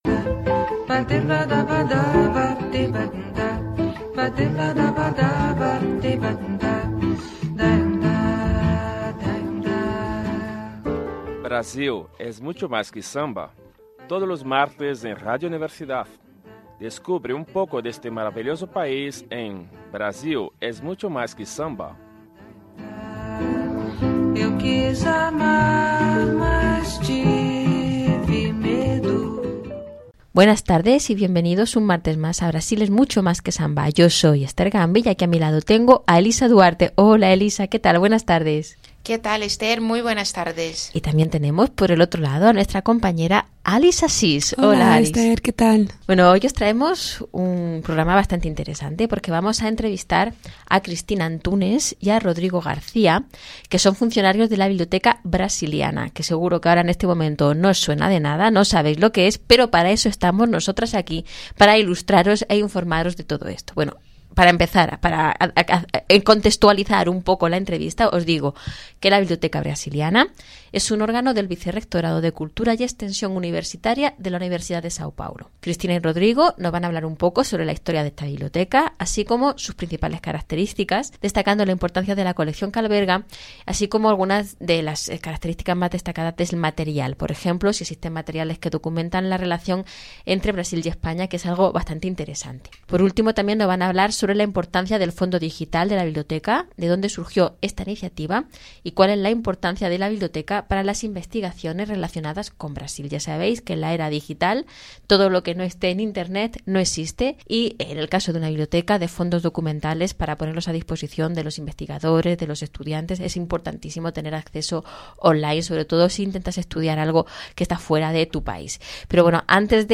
Brasil es mucho más que samba: Entrevista - E-LIS repository